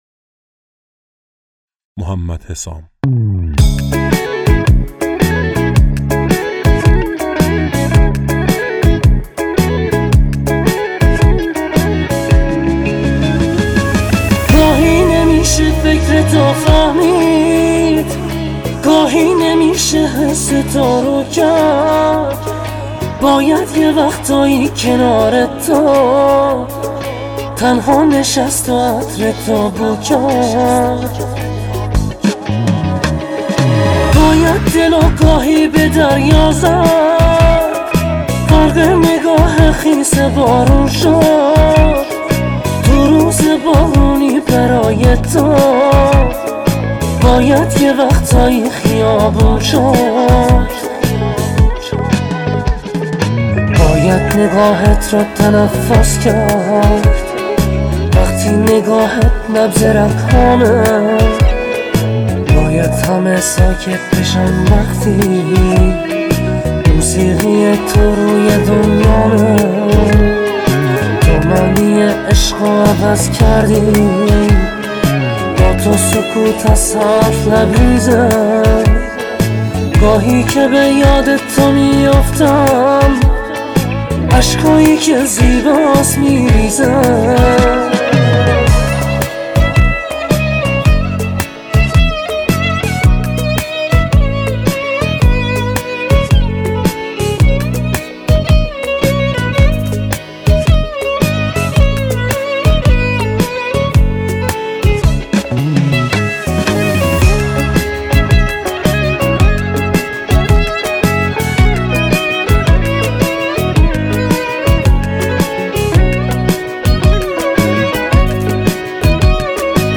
صدایی خیلی نزدیک به مرحوم مرتضی پاشایی